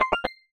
Universal UI SFX / Basic Menu Navigation
Menu_Navigation03_Save.wav